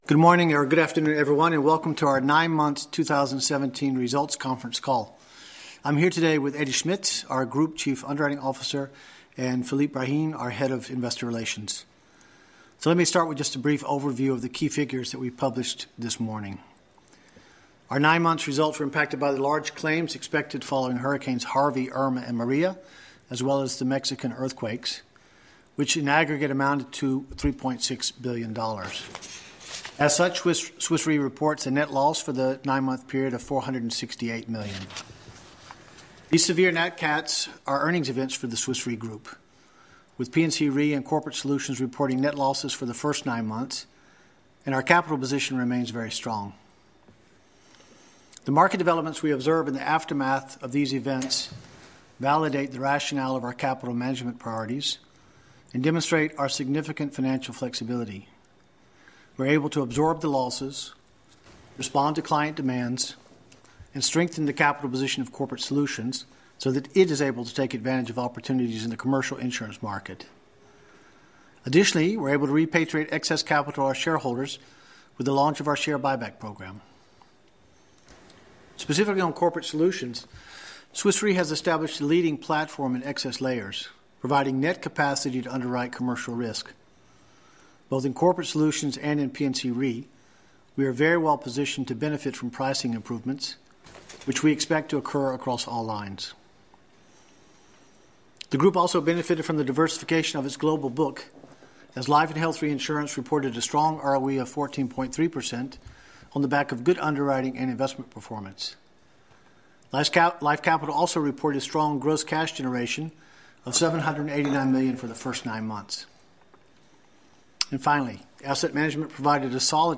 Analysts Conference call recording
2017_nine_months_qa_audio.mp3